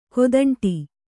♪ kodaṇṭi